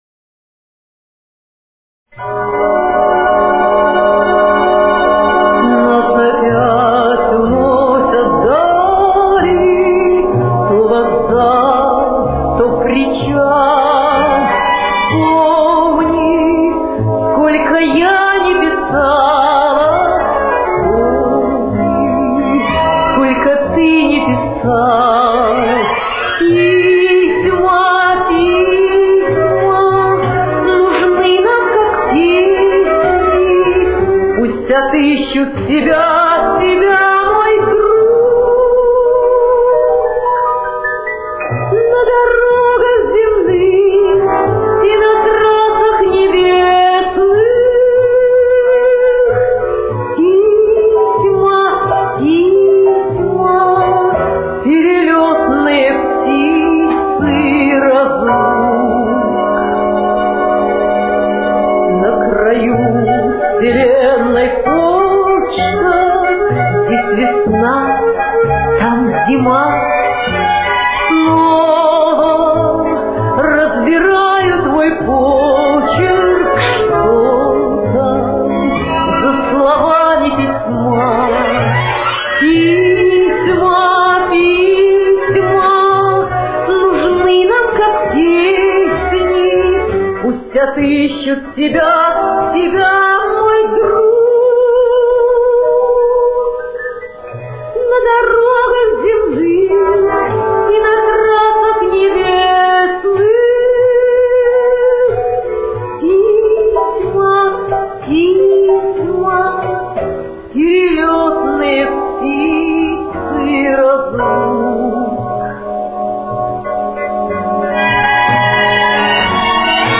Темп: 62.